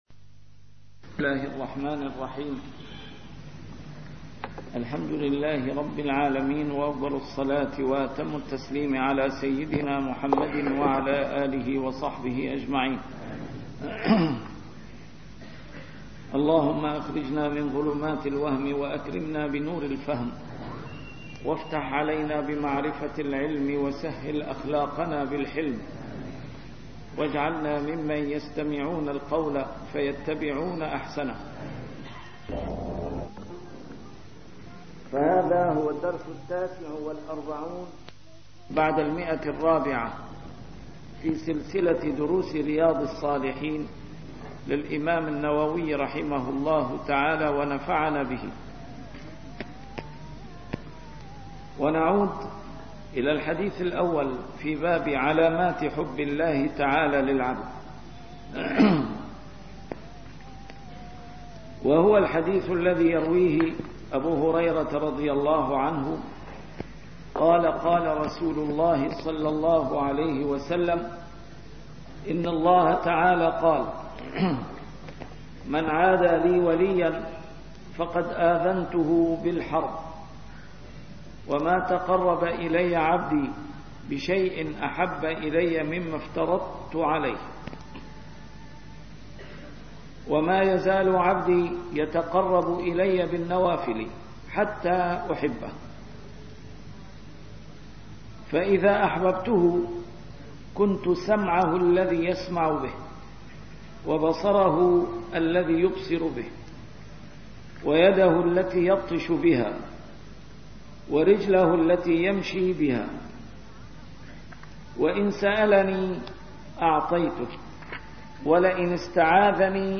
A MARTYR SCHOLAR: IMAM MUHAMMAD SAEED RAMADAN AL-BOUTI - الدروس العلمية - شرح كتاب رياض الصالحين - 449- شرح رياض الصالحين: علامات حب الله للعبد